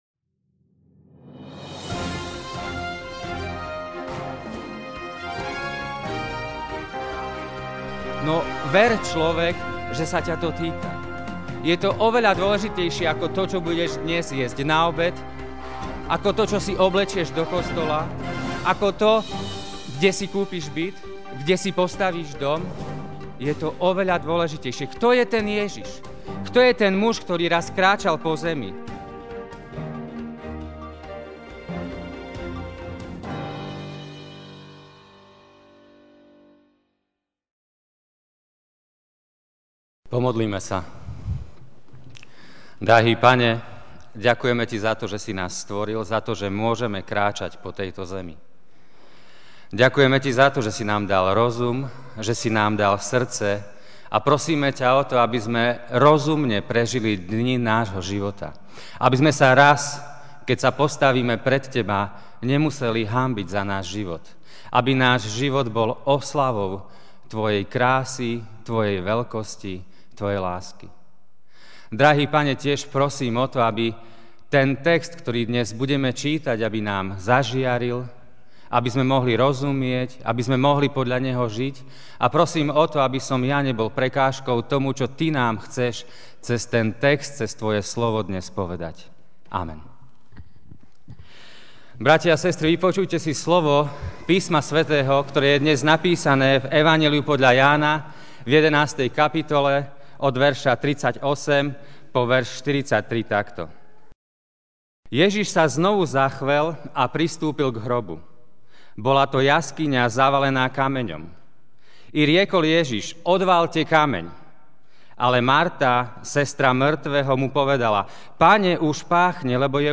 Ranná kázeň: Odvaľ kameň!